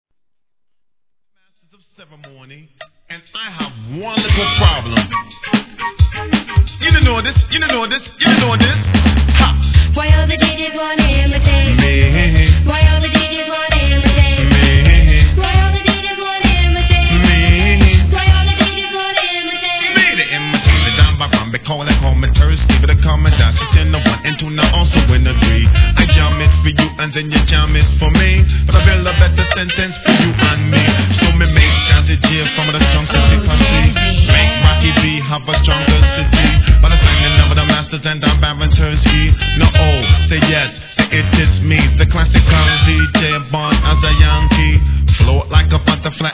HIP HOP/R&B
ラガHIP HOP CLASSIC!